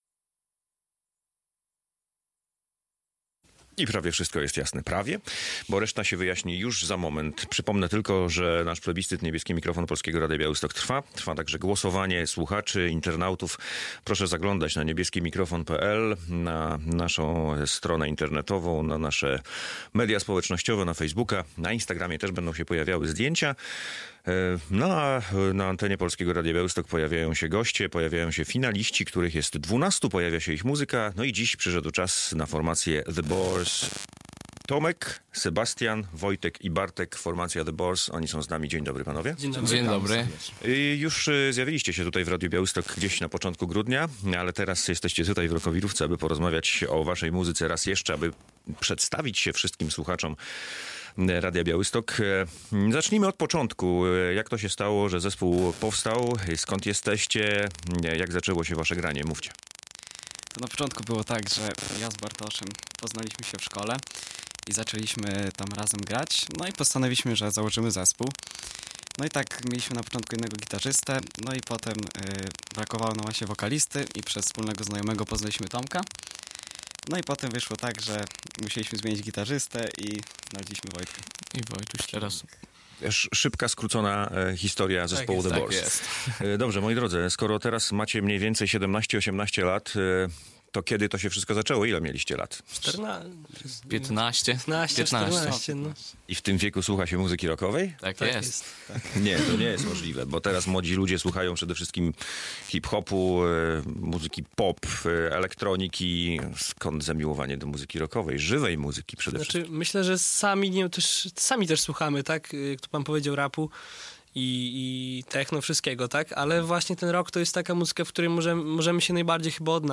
Radio Białystok | Gość | The Boars - finaliści plebiscytu "Niebieski Mikrofon"